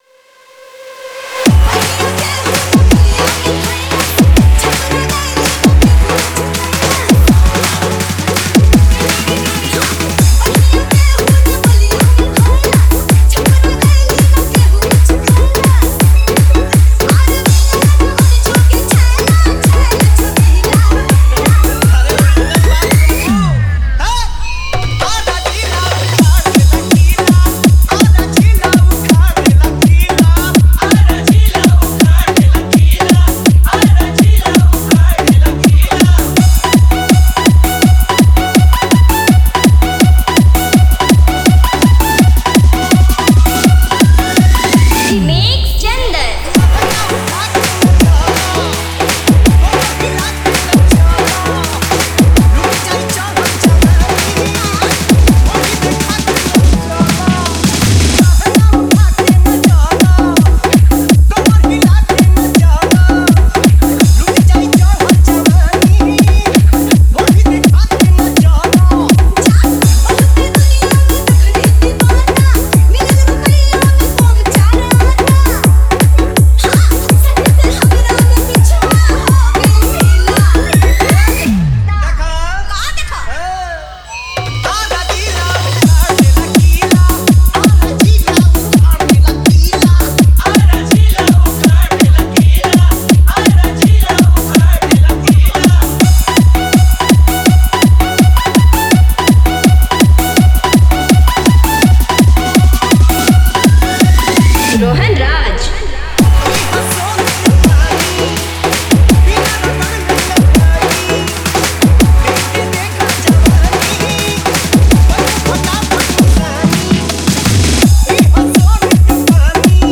Bhojpuri Dj Song